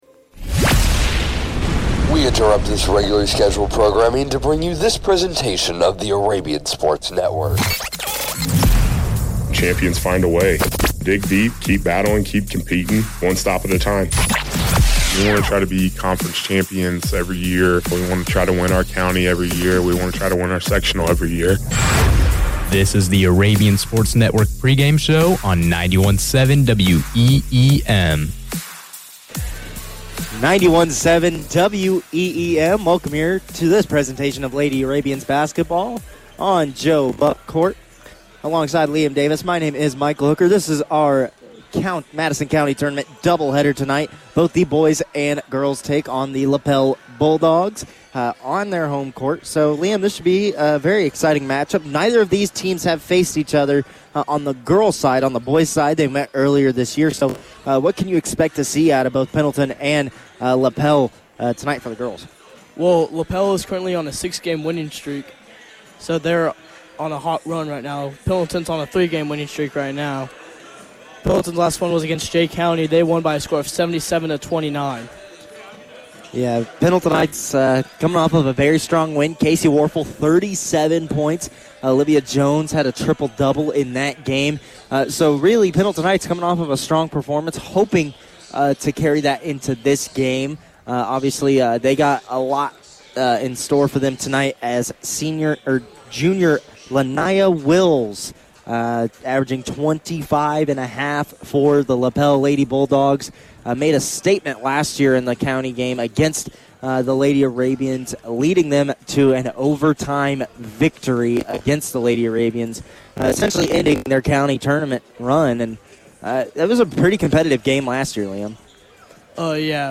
Varsity Boys Basketball Broadcast Replay Pendleton Heights vs. Anderson 1-7-25